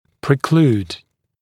[prɪ’kluːd][при’клу:д]исключать; препятствовать; мешать; предотвращать; устранять